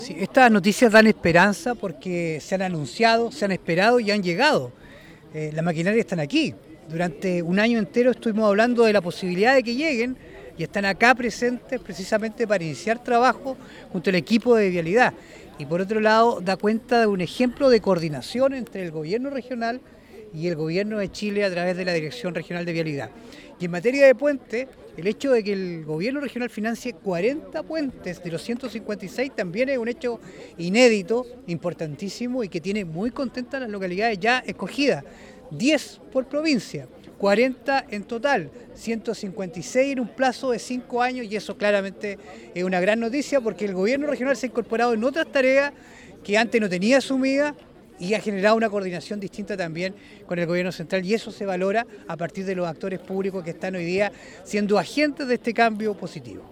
En tanto, el Consejero Regional por la Provincia de Osorno, Francisco Reyes enfatizó en que hubo espera por parte de los vecinos para que comiencen los distintos trabajos en sus sectores, lo que se realiza gracias a una coordinación y cooperación entre el Gobierno Regional y el Gobierno de Chile.